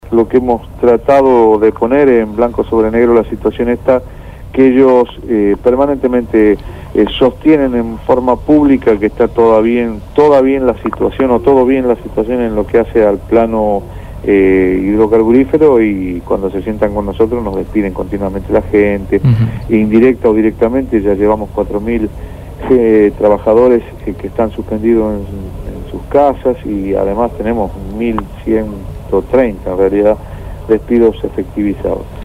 entrevistaron a Alberto Roberti, Secretario General de de la Federación Argentina Sindical de Petróleo y Gas Privados (FASPyGP).